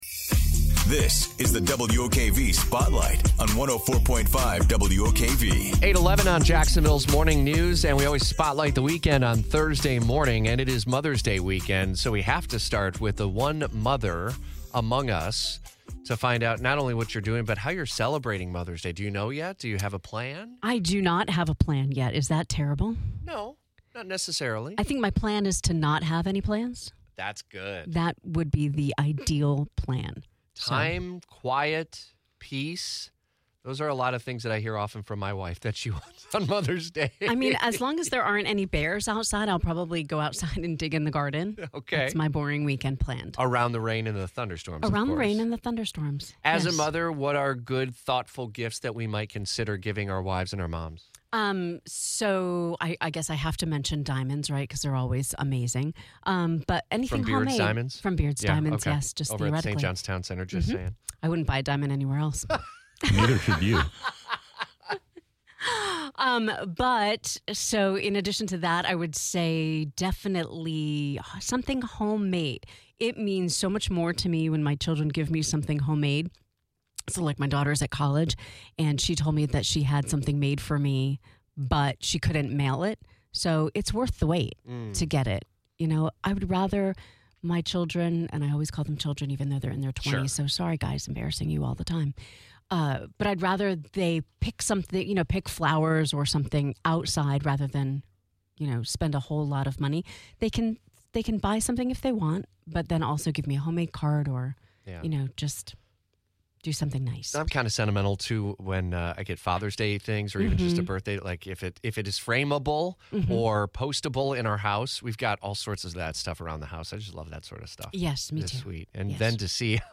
SPOTLIGHT: Every Thursday morning the Jacksonville’s Morning News team